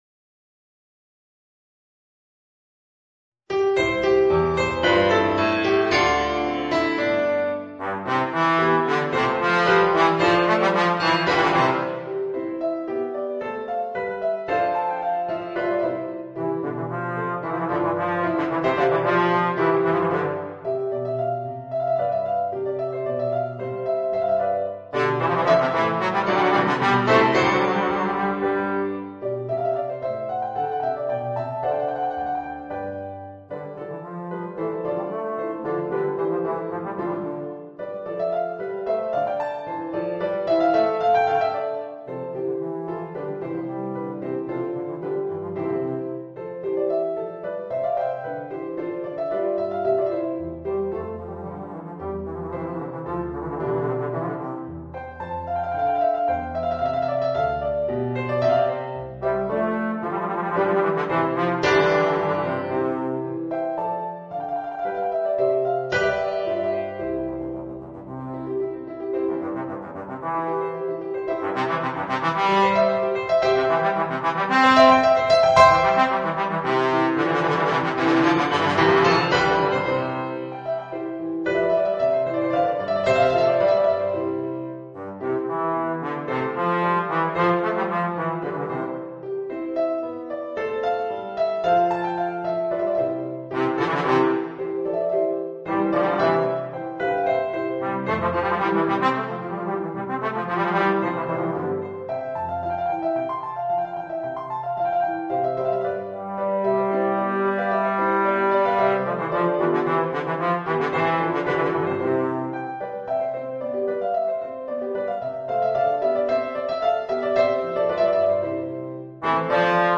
Voicing: Bass Trombone and Organ